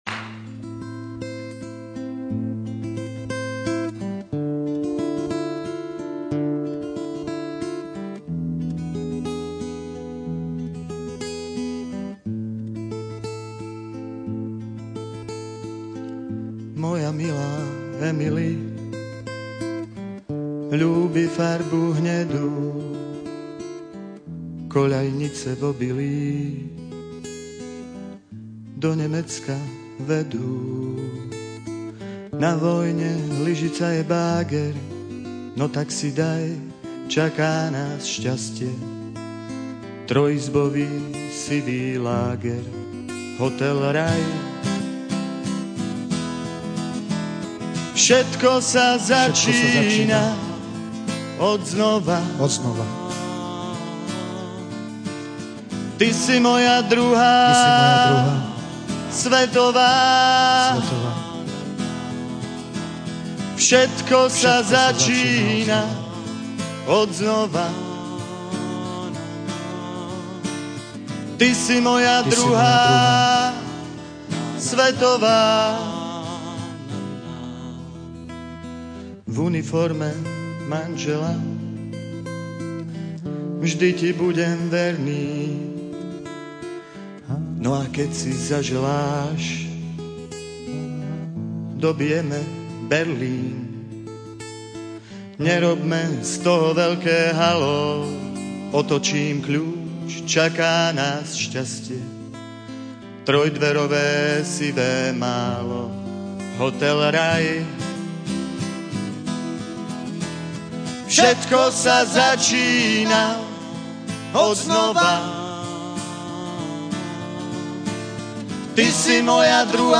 Ukážky z tvorby pre rozhlas